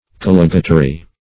Search Result for " collegatary" : The Collaborative International Dictionary of English v.0.48: Collegatary \Col*leg"a*ta*ry\, n. [L. collegetarius.